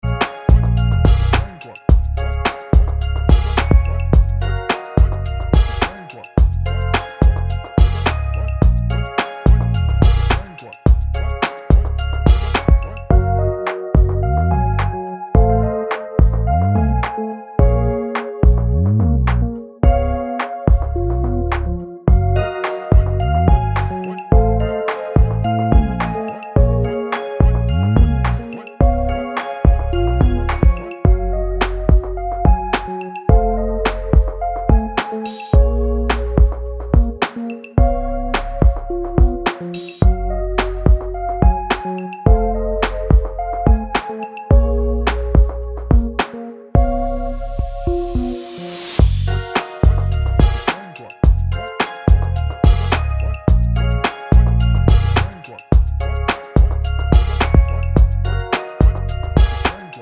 Christmas